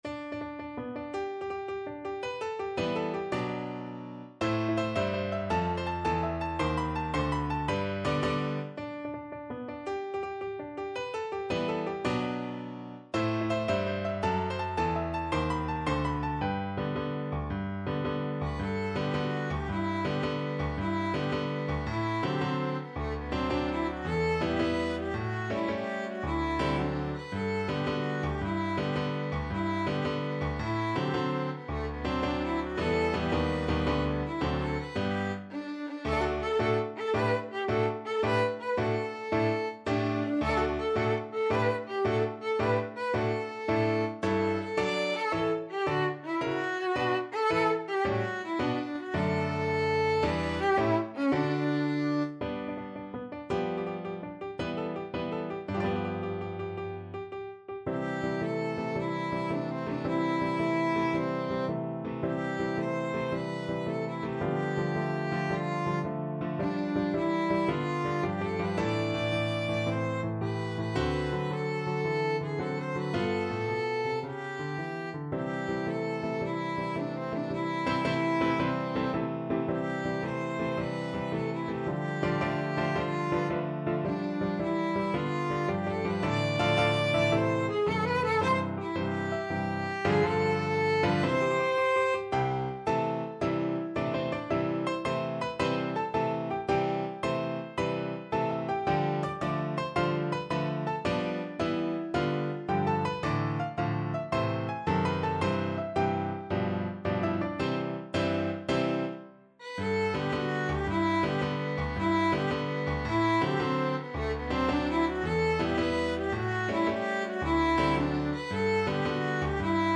Viola
6/8 (View more 6/8 Music)
G major (Sounding Pitch) (View more G major Music for Viola )
March .=c.110
Classical (View more Classical Viola Music)